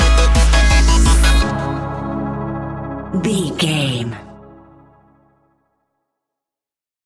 Ionian/Major
C♯
electronic
techno
trance
synthesizer
synthwave
instrumentals